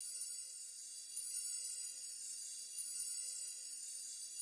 shimmering_loop.wav